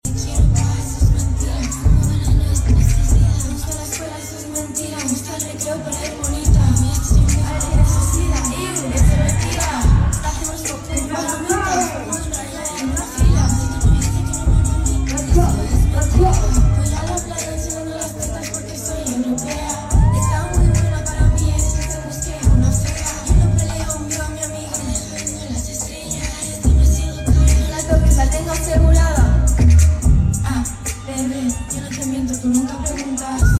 en vivo en el Lollapalooza Chile